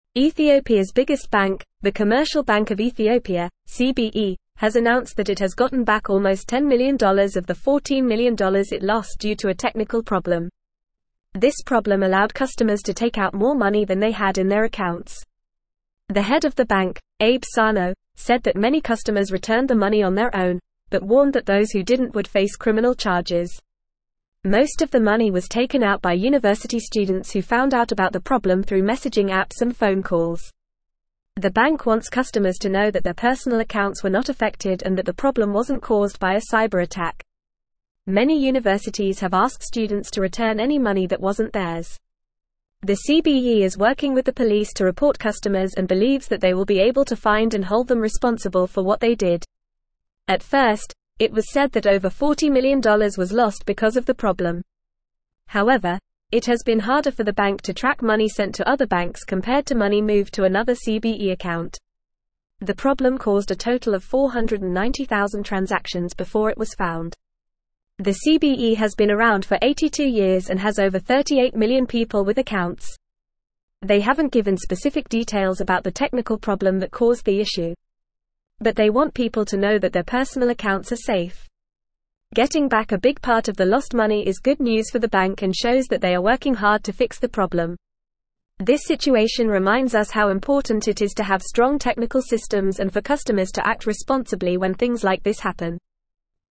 Fast
English-Newsroom-Upper-Intermediate-FAST-Reading-Ethiopias-Commercial-Bank-Recovers-10-Million-Lost-in-Glitch.mp3